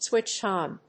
アクセントswítched‐ón